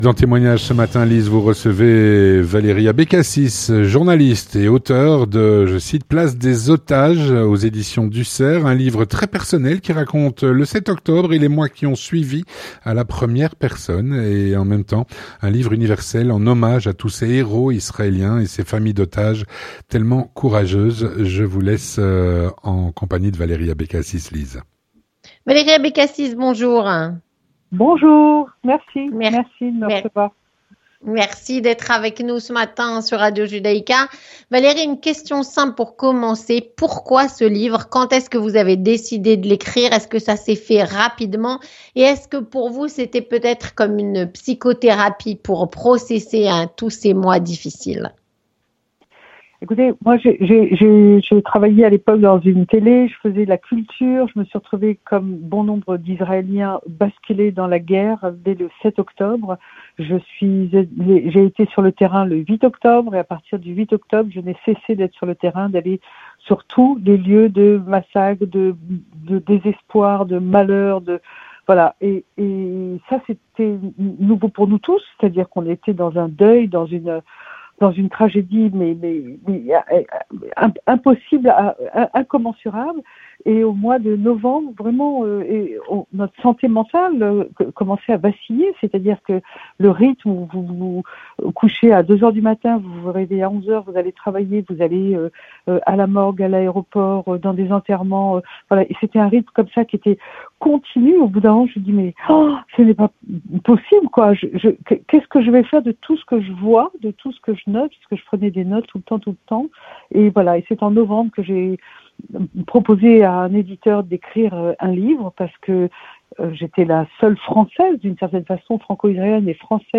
Témoignage - “Place des otages” (Éditions du Cerf).